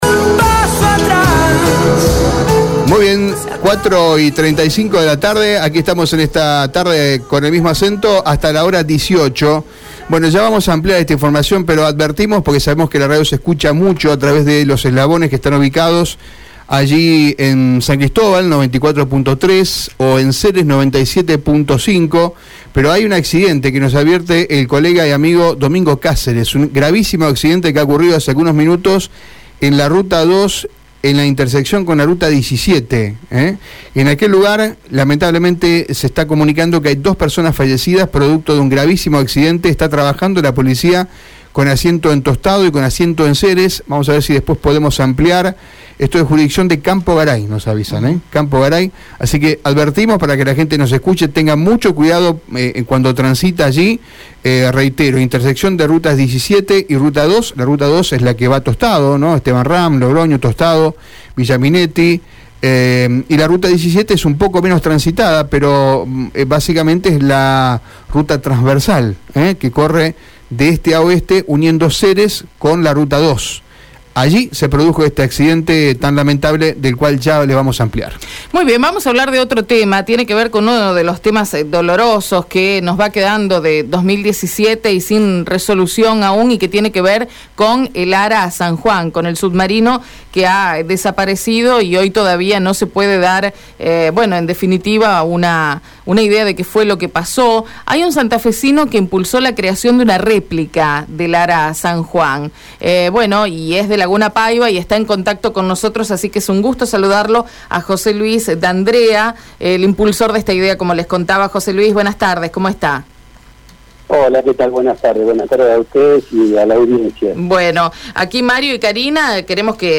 Con la voz entrecortada, pidió a las autoridades nacional que no abandonen la búsqueda.